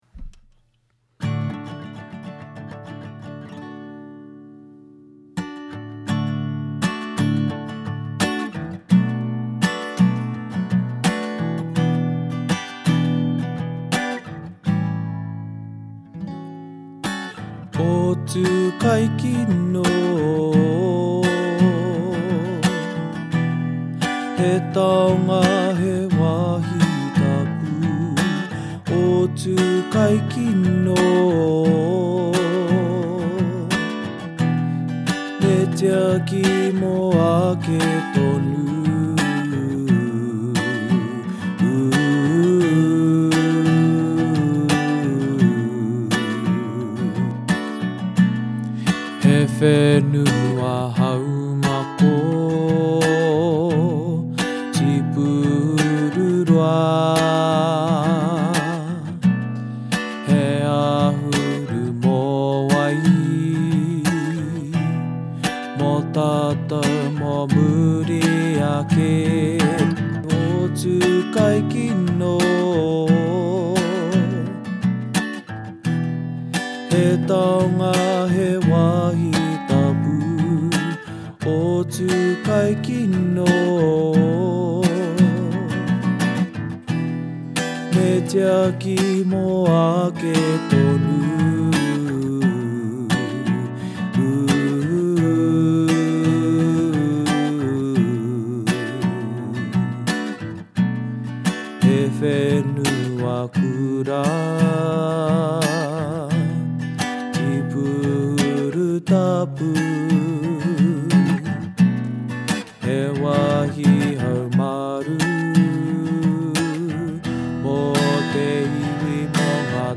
Ōtūkaikino waiata.mp3